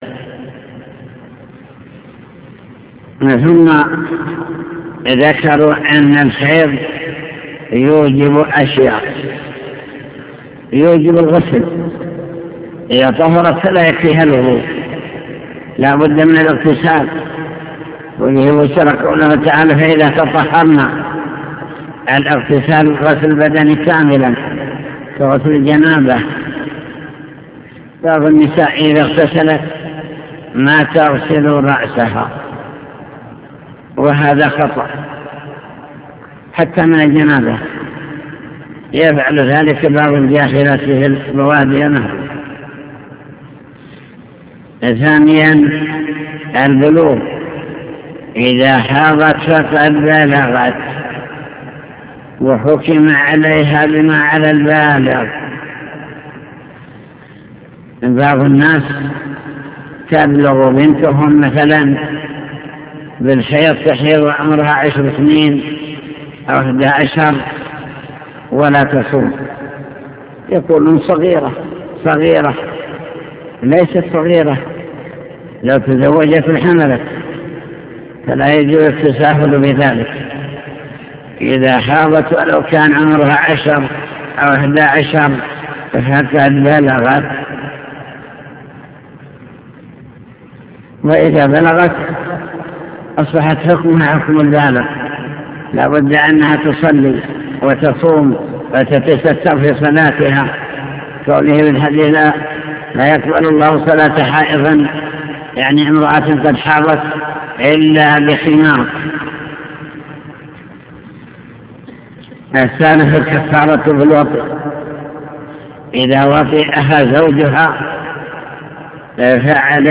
المكتبة الصوتية  تسجيلات - كتب  شرح كتاب دليل الطالب لنيل المطالب كتاب الطهارة باب الحيض